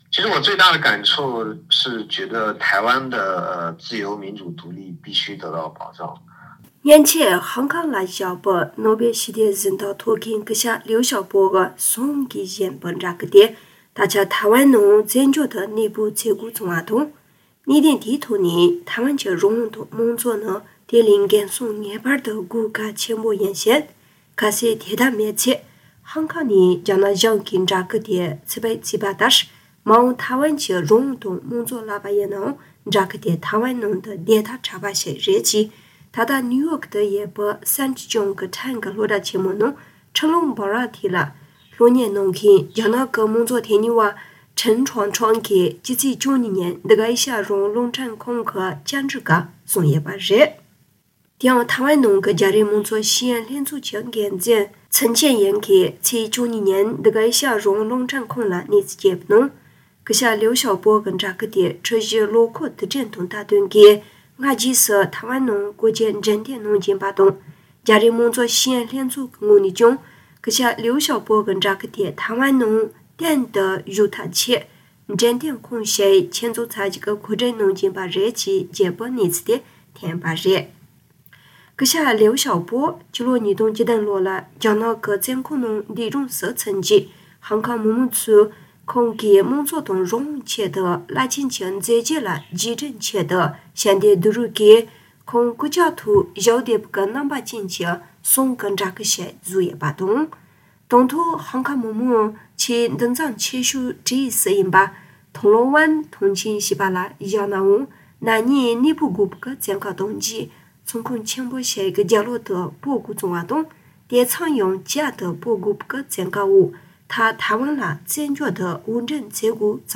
སྒྲ་ལྡན་གསར་འགྱུར། སྒྲ་ཕབ་ལེན།
གསར་འགོད་པ།